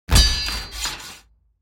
دانلود صدای تصادف 14 از ساعد نیوز با لینک مستقیم و کیفیت بالا
جلوه های صوتی